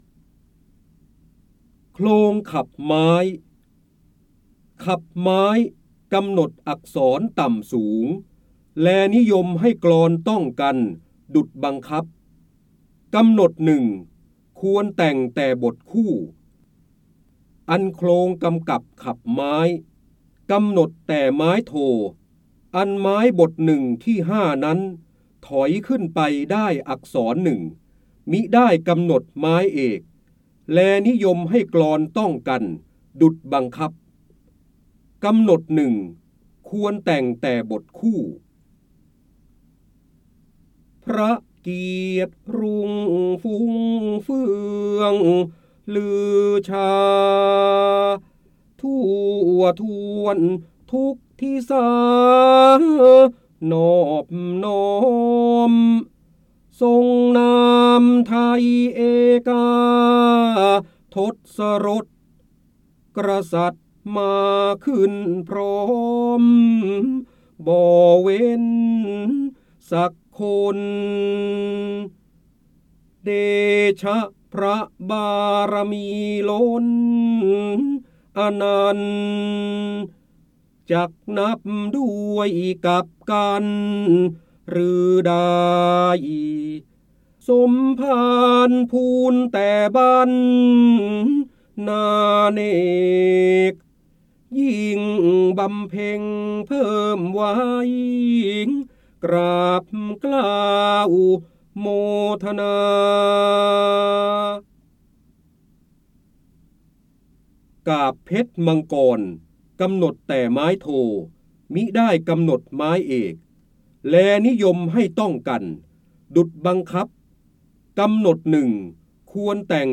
เสียงบรรยายจากหนังสือ จินดามณี (พระโหราธิบดี) โคลงขับไม้
คำสำคัญ : พระเจ้าบรมโกศ, พระโหราธิบดี, ร้อยแก้ว, การอ่านออกเสียง, จินดามณี, ร้อยกรอง